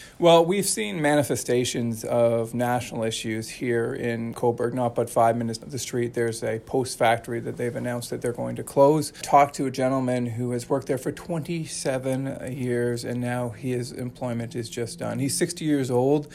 We chatted with Lawrence at his campaign office in Cobourg to get a sense of the main issues he is hearing at the door.